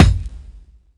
Break_kick_16.wav